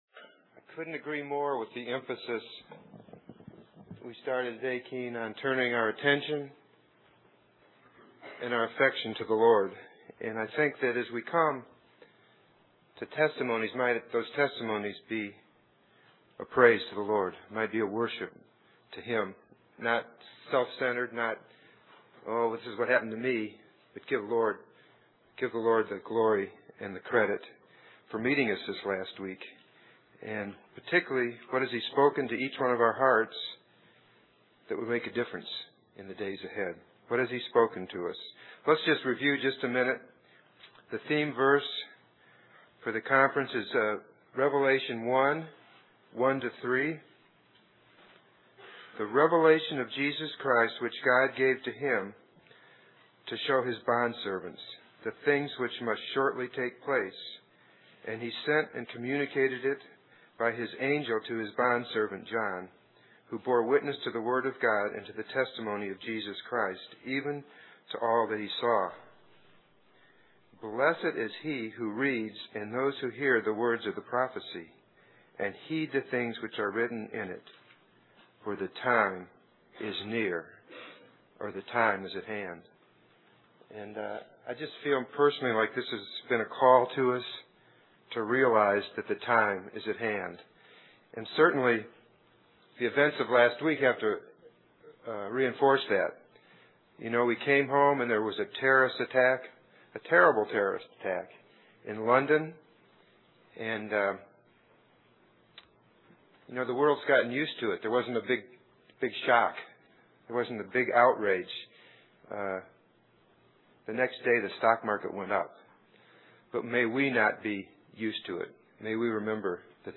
Testimonies And Sharing from 2005 Christian Family Conference